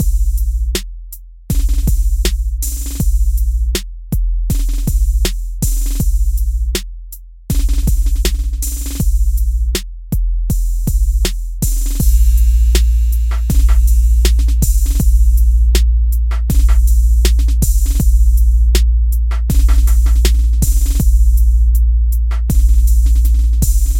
旧学校 鼓声循环19
描述：嘻哈...
Tag: 80 bpm Hip Hop Loops Drum Loops 4.04 MB wav Key : Unknown